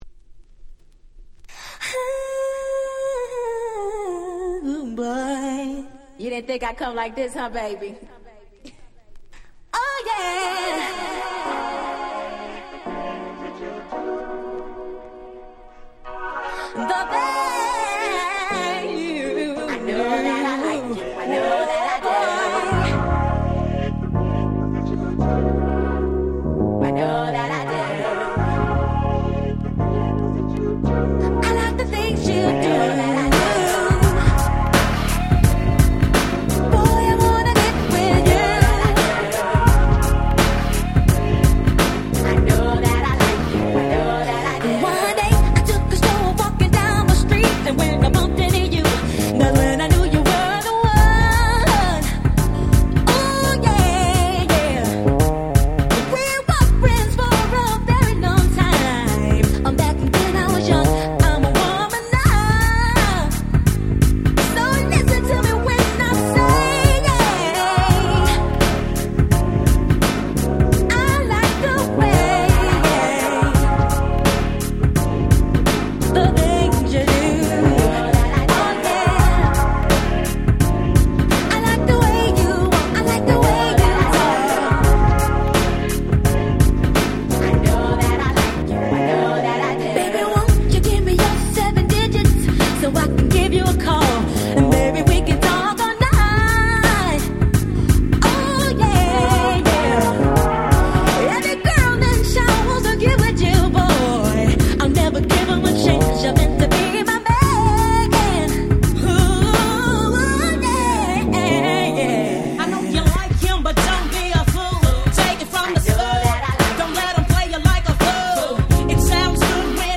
94' Nice R&B / Hip Hop Soul !!